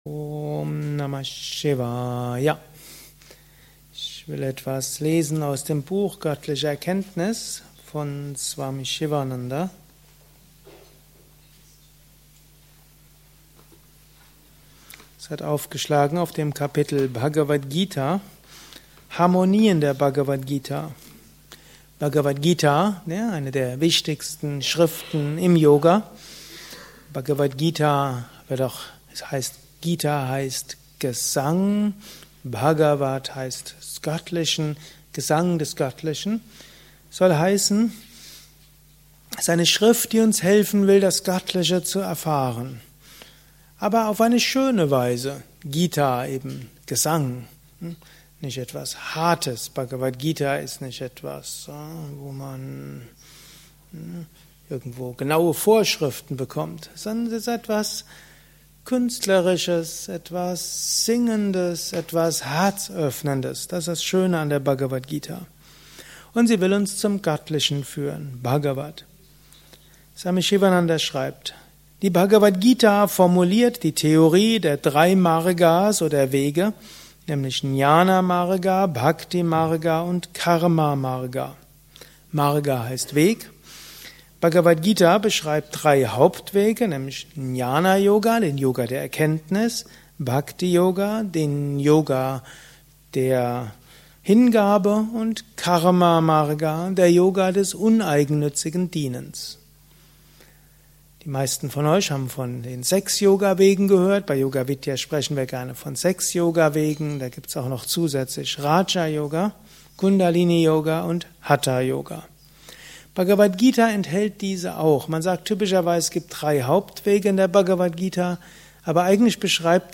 Gelesen im Anschluss nach einer Meditation im Haus Yoga Vidya Bad Meinberg.
Lausche einem Vortrag über: Harmonie der Bhagavad Gita